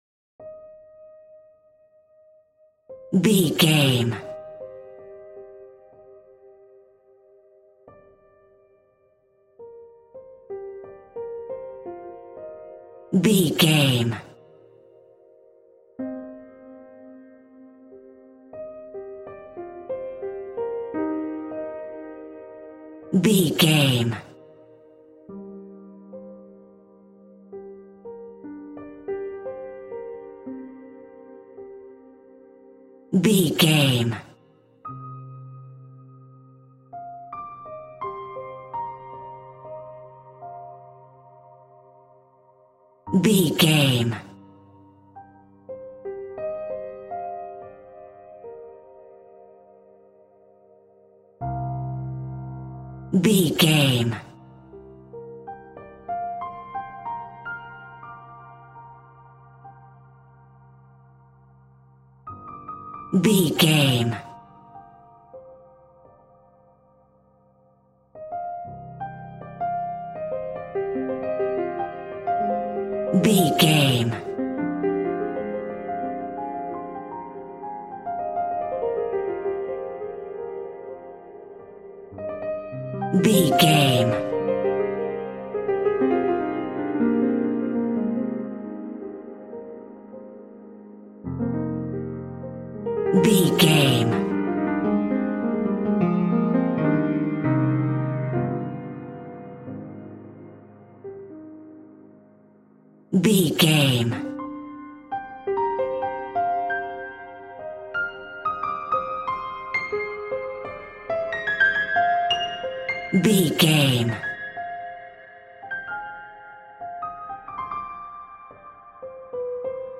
Aeolian/Minor
scary
ominous
dark
haunting
eerie
mournful
instrumentals
horror piano